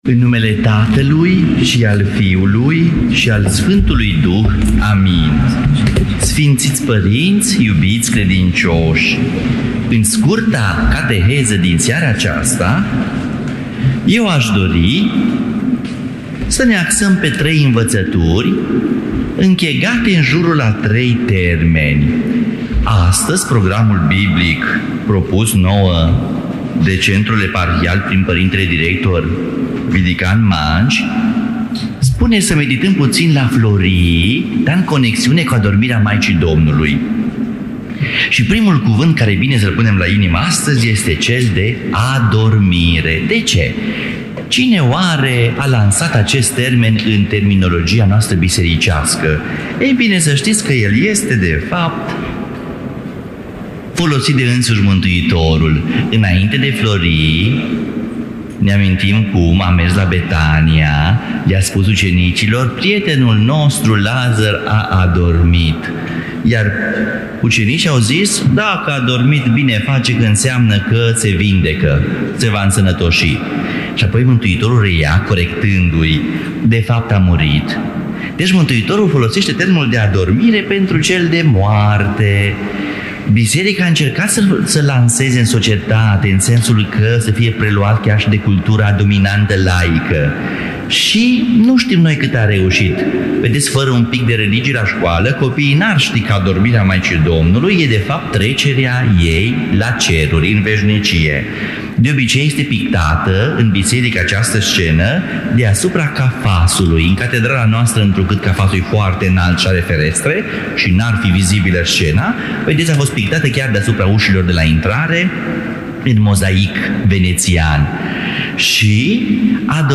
Cateheză
Cuvinte de învățătură